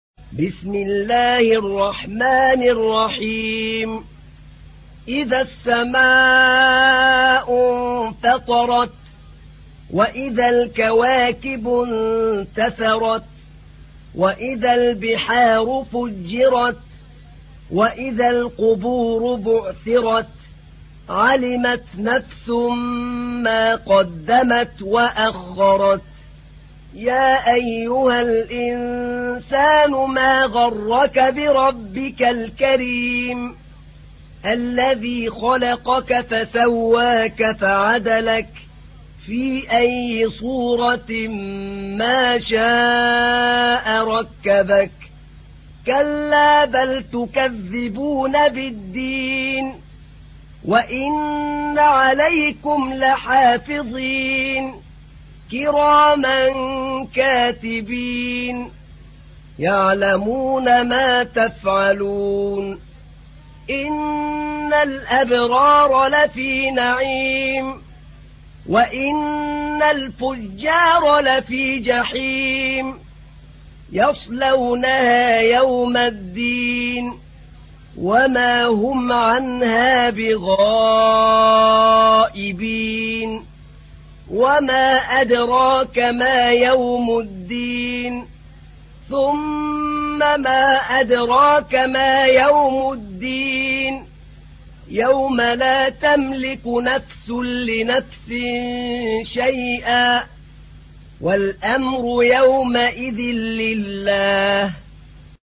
تحميل : 82. سورة الانفطار / القارئ أحمد نعينع / القرآن الكريم / موقع يا حسين